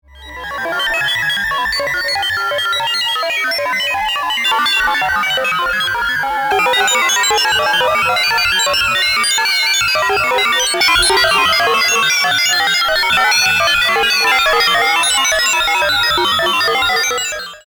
昔ながらの「コンピューター」風の演出に活躍しそうです。
空間への広がり方が綺麗です。